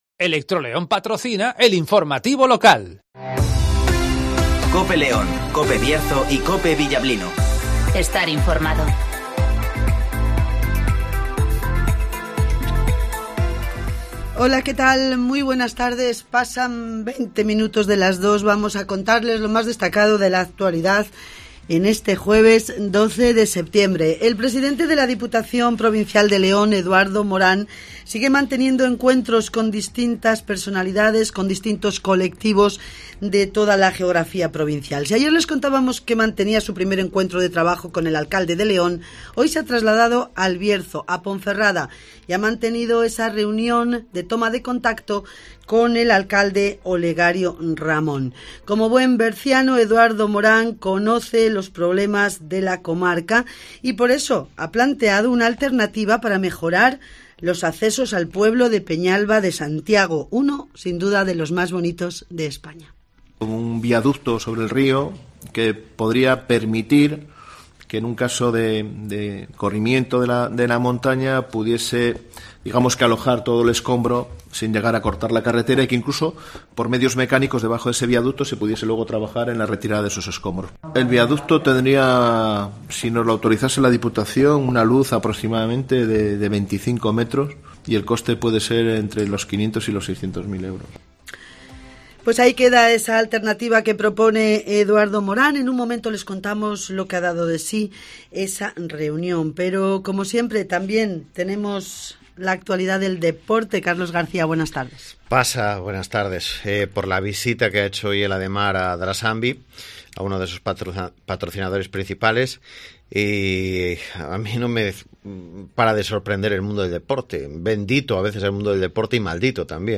- Eduardo Morán ( Presidente de la Diputación de León )